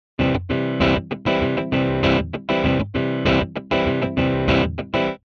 ▶SEQによるパターン演奏の例